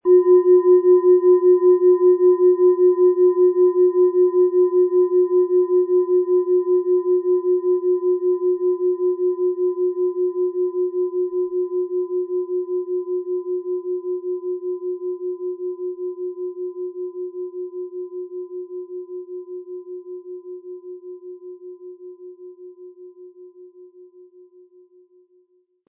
Alte Klangschalen - gesammelte Unikate
Um den Originalton der Schale anzuhören, gehen Sie bitte zu unserer Klangaufnahme unter dem Produktbild.
PlanetentonWasser
SchalenformBihar
MaterialBronze